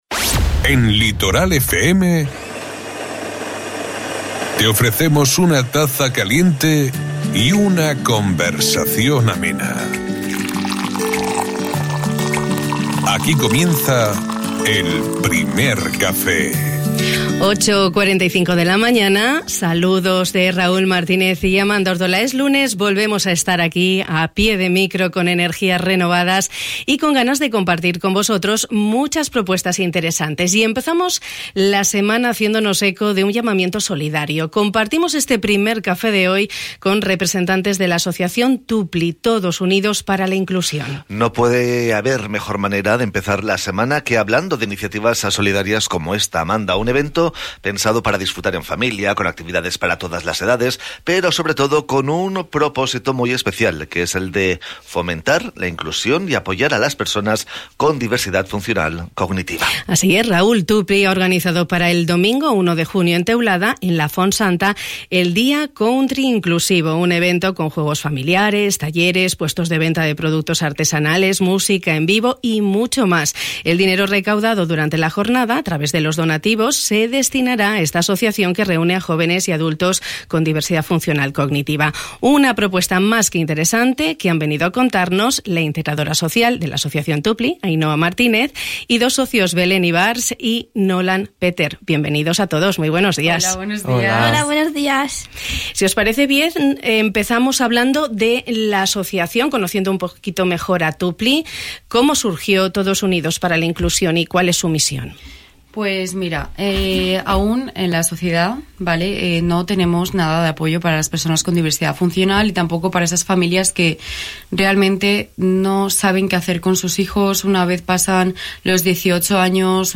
En el Primer Café de Radio Litoral hemos empezado la semana haciéndonos eco de un llamamiento solidario. Hemos compartido con representantes de la Asociación TUPLI, Todos Unidos Para La Inclusión, una interesante iniciativa solidaria.